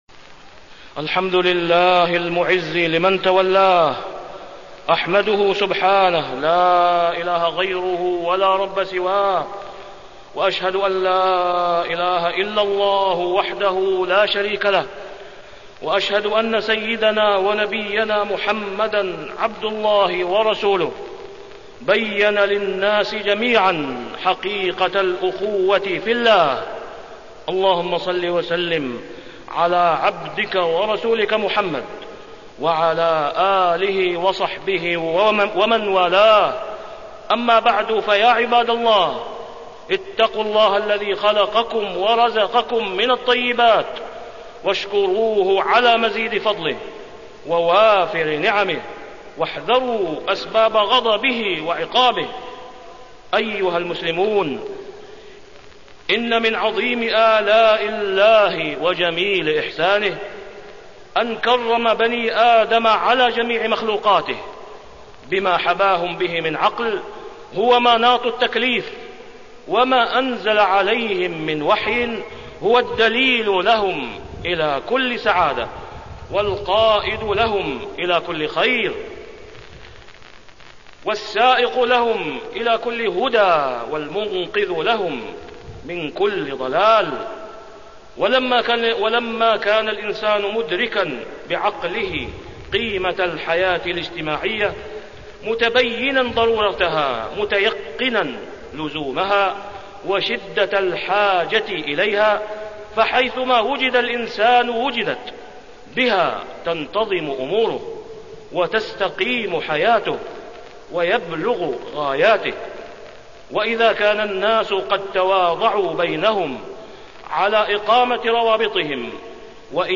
تاريخ النشر ١٨ جمادى الآخرة ١٤٢٧ هـ المكان: المسجد الحرام الشيخ: فضيلة الشيخ د. أسامة بن عبدالله خياط فضيلة الشيخ د. أسامة بن عبدالله خياط رابطة العقيدة